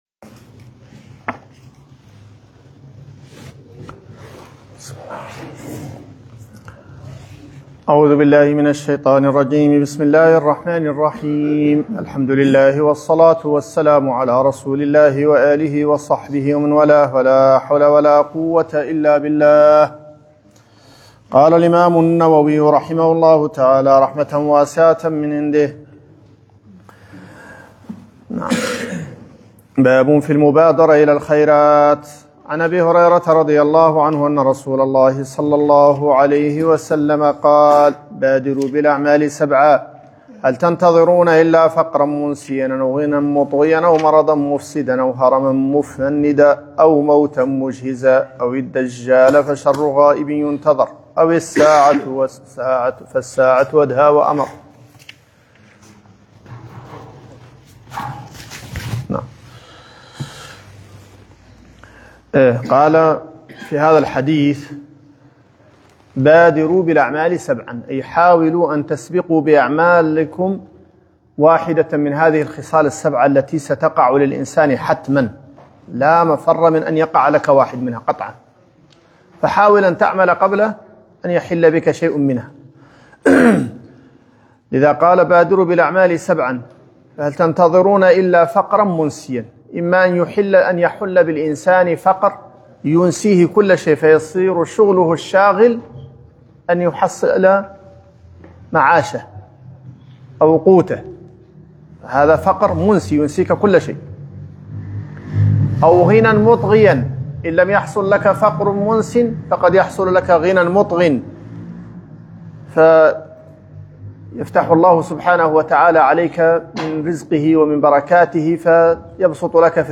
رياض الصالحين الدرس 09